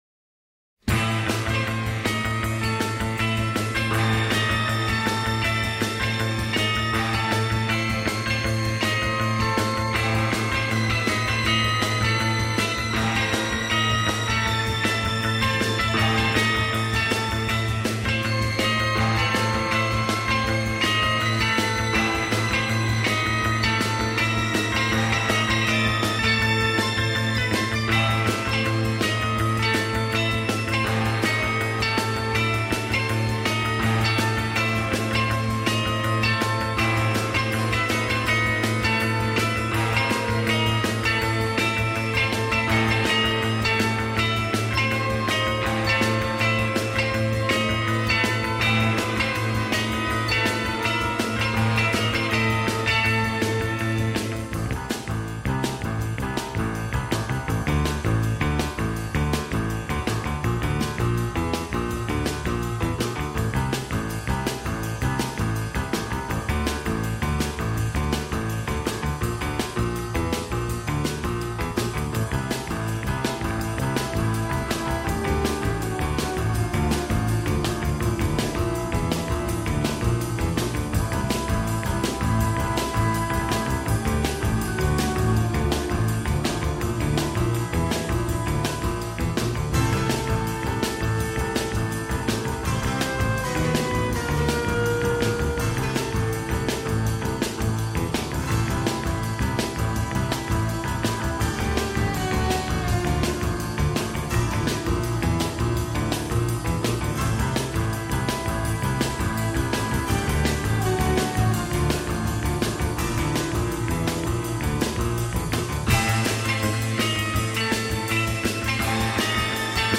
il y a surtout dans cette musique un spleen fascinant
Guimbarde, sifflement, guitare et basse électrique…